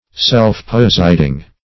Search Result for " self-positing" : The Collaborative International Dictionary of English v.0.48: Self-positing \Self`-pos"it*ing\, a. The act of disposing or arranging one's self or itself.